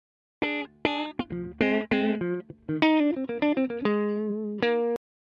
作例は、ただ単にギター系のプリセットをそのまま使っただけで、特に意図はありません。
\Audio Loops\Smart Loops\Electric Guitars sample loops\Mid Tempo loops\フォルダの
（キー F /テンポ 100 に変更）